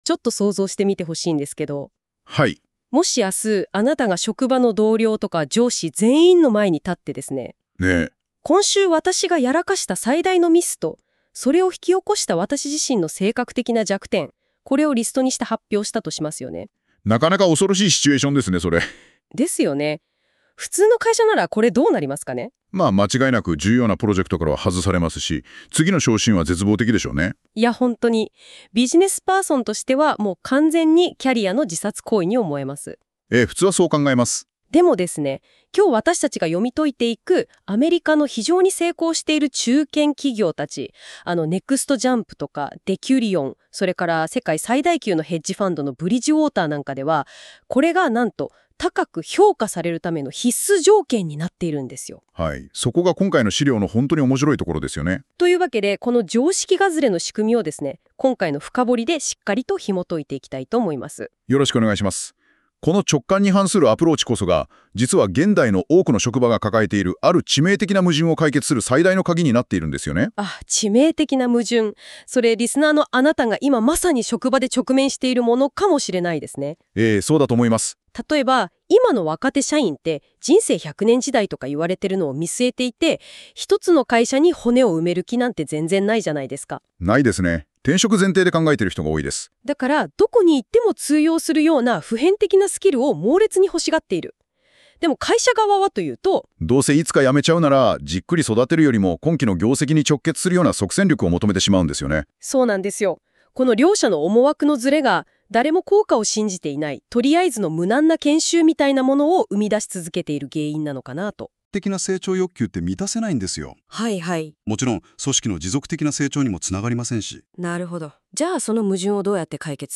この記事の解説を対話形式の音声ファイル（mp4）で再生（18分） ↓ 会社と社員の関係が、静かだが確実に変わりつつある。